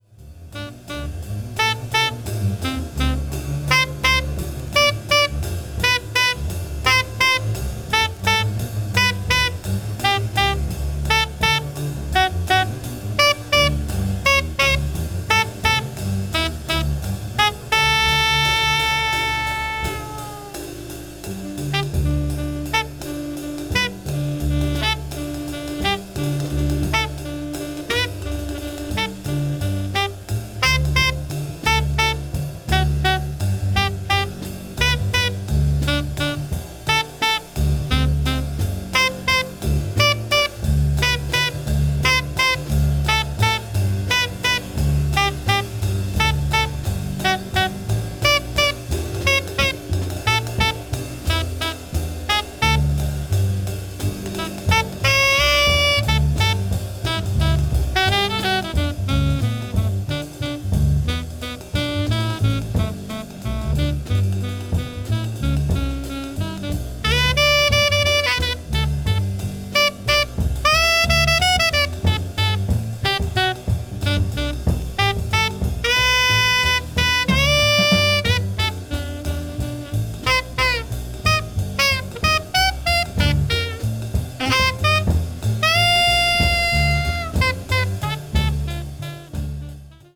avant-jazz   free jazz   post bop